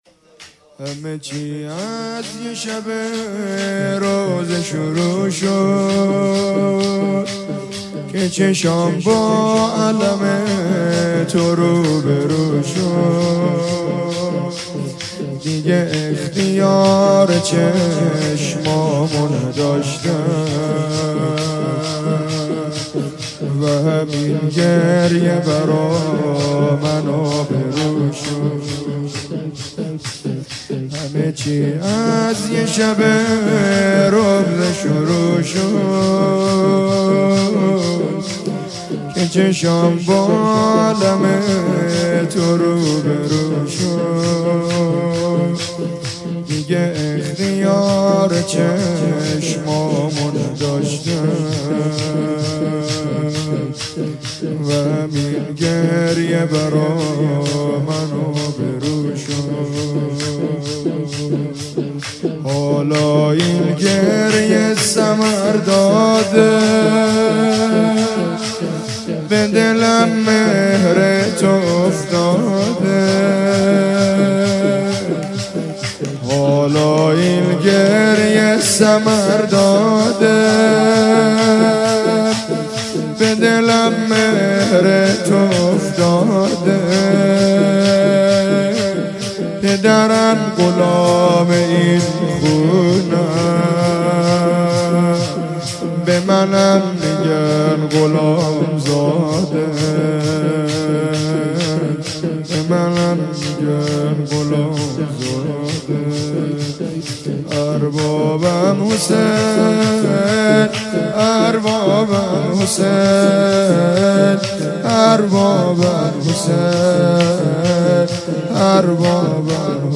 شور
روضه حضرت صدیقه شهیده علیها سلام